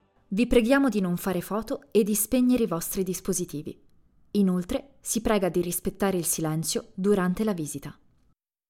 Kommerziell, Tief, Vielseitig, Freundlich, Warm
Telefonie